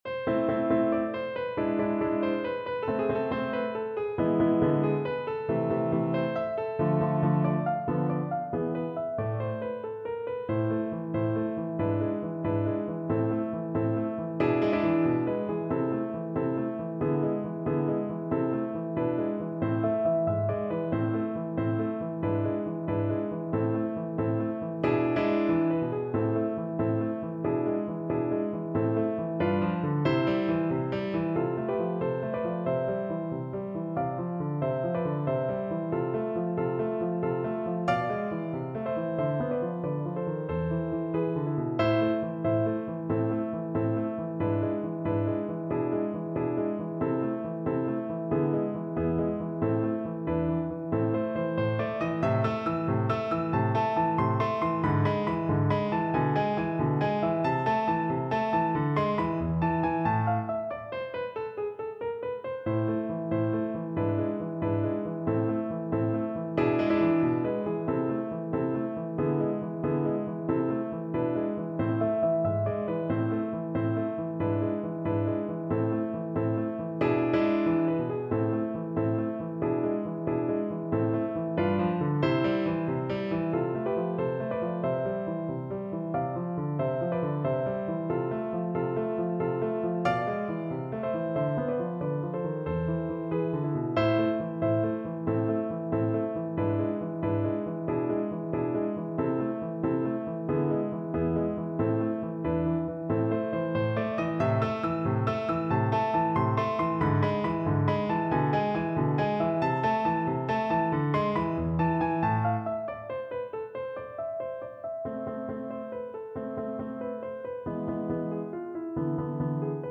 Play (or use space bar on your keyboard) Pause Music Playalong - Piano Accompaniment Playalong Band Accompaniment not yet available transpose reset tempo print settings full screen
Flute
A minor (Sounding Pitch) (View more A minor Music for Flute )
4/4 (View more 4/4 Music)
Allegretto affettuoso =92
Classical (View more Classical Flute Music)